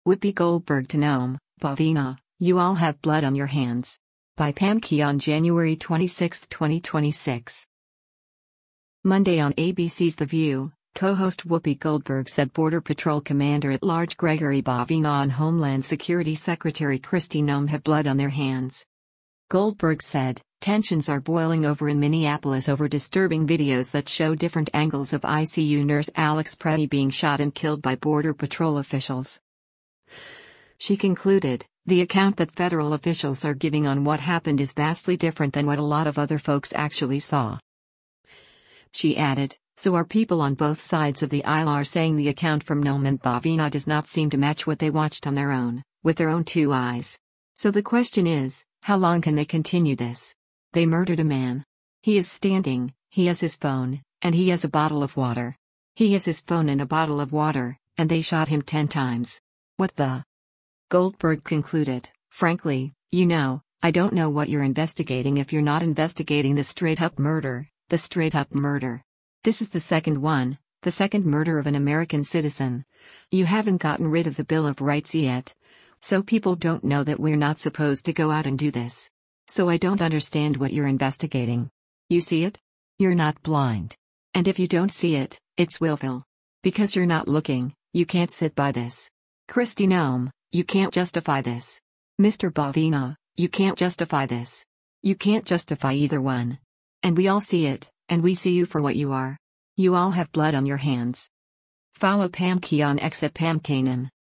Monday on ABC’s “The View,” co-host Whoopi Goldberg said Border Patrol Commander at Large Gregory Bovino and Homeland Security Secretary Kristi Noem have “blood” on their “hands.”